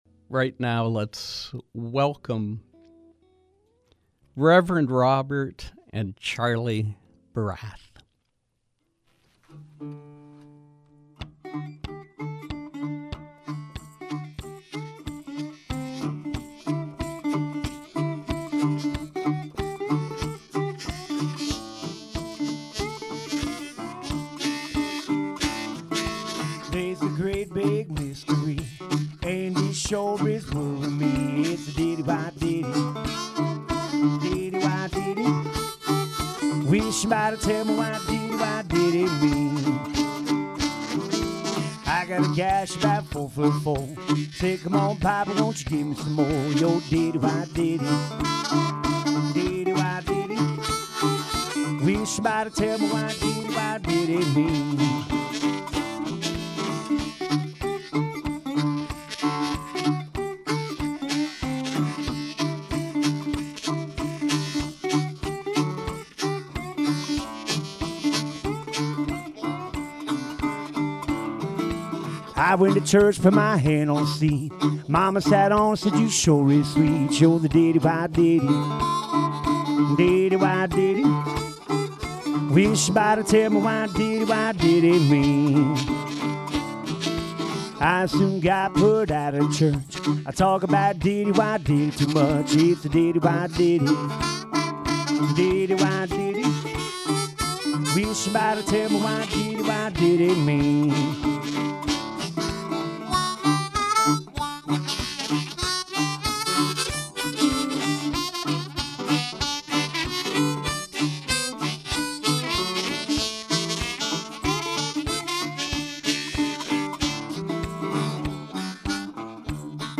Live acoustic blues